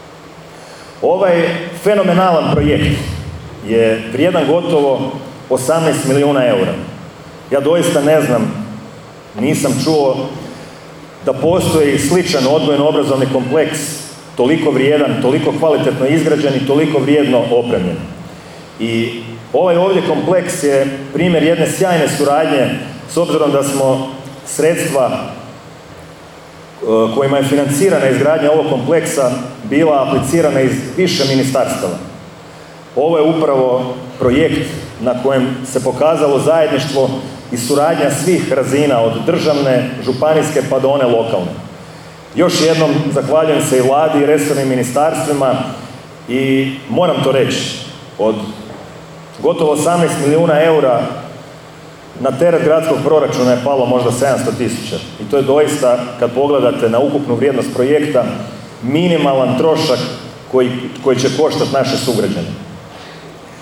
U najkraćem mogućem roku poduzeti su svi potrebni koraci kako bi se Odgojno-obrazovni kompleks Galdovo stavio u punu funkciju i konačno vratio djeci.i Gradonačelnik Orlić zahvalio je svima na strpljenju i potpori